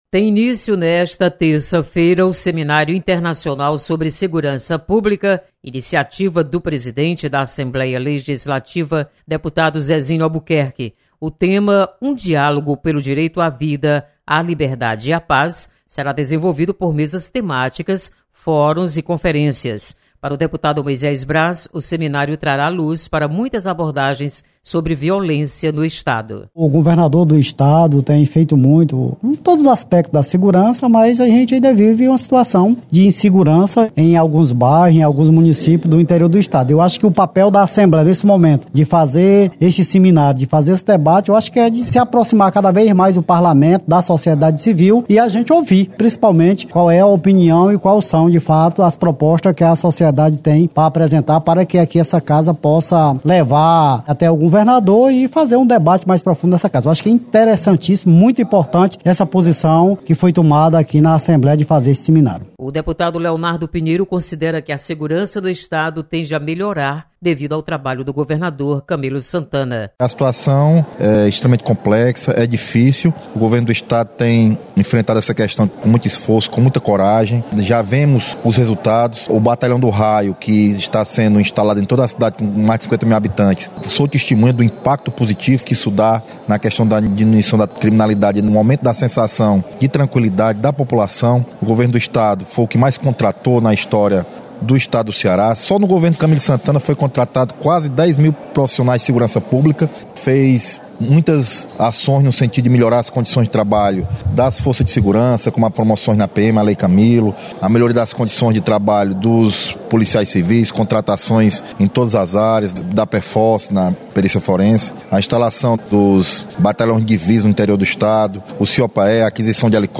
Tem início nesta terça-feira, o Seminário Internacional sobre Segurança Pública. Repórter